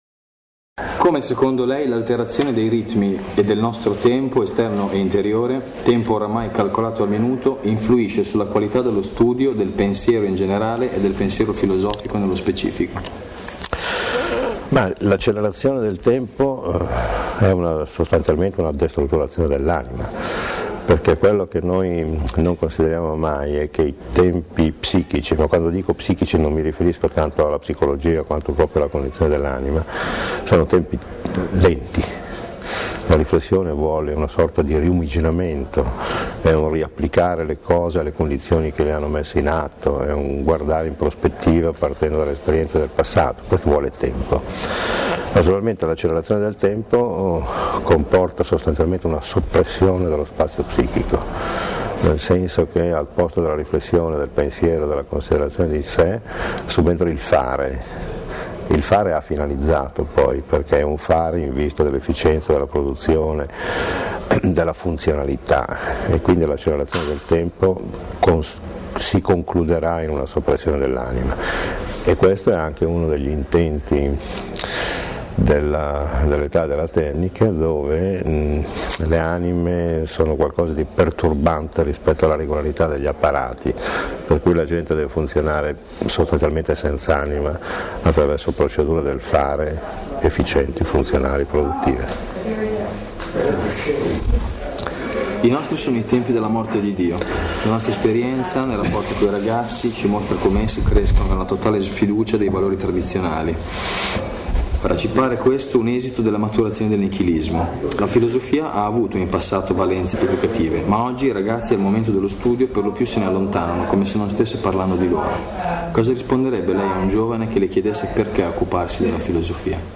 Intervista a Umberto Galimberti, docente alle Vacances de l'Esprit 2006
Intervista esclusiva del Centro Studi ASIA al prof. Umberto Galimberti, filosofo e psicoanalista di enorme fama nonche' docente alle Vacances de l'Esprit 2006